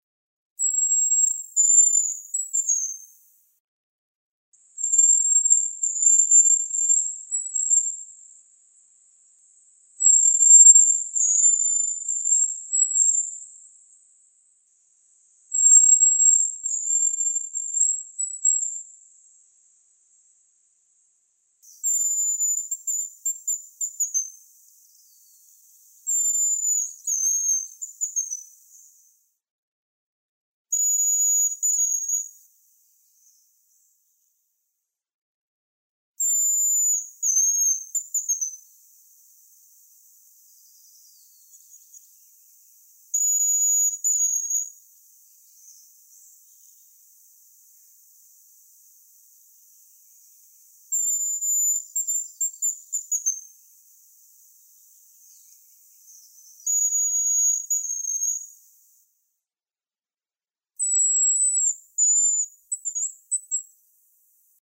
Звуки рябчика
Звук рябчика свист самки